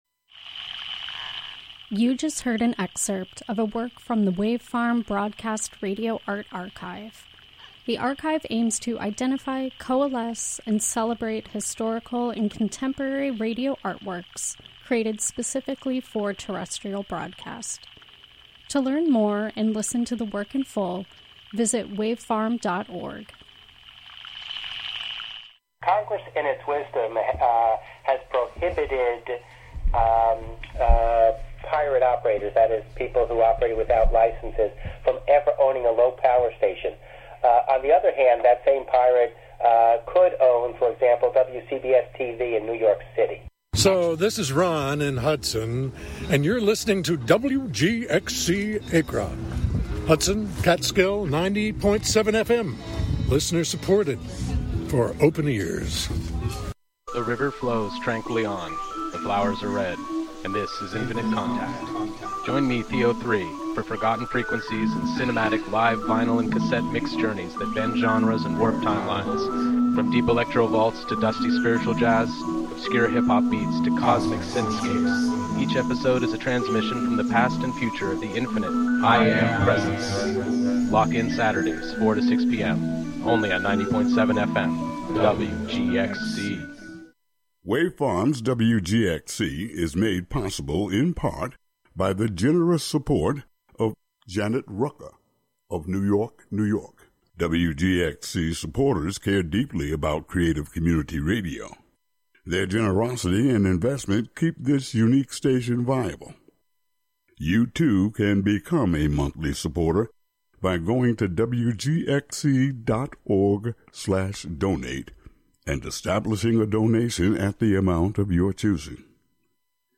Wednesday Afternoon Show